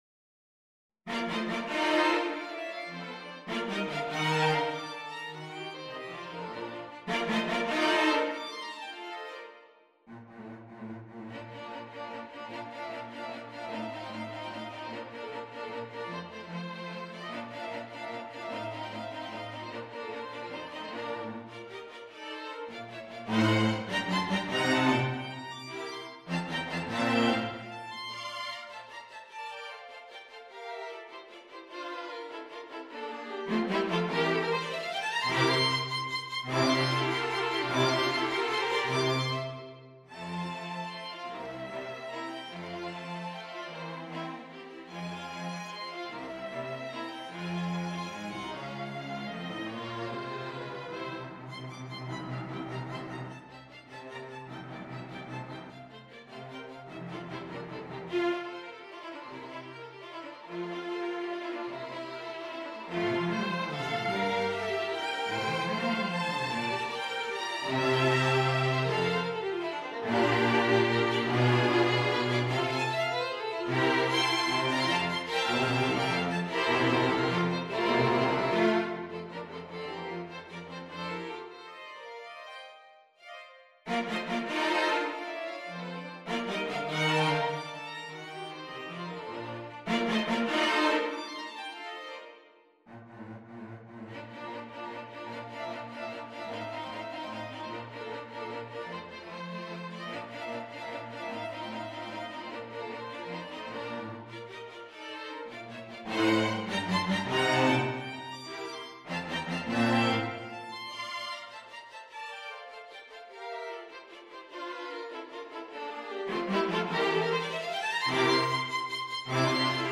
Violin 1 Violin 2 Viola Cello
Instrument: String Quartet
Style: Classical
haydn_string_quartet_op67_3.mp3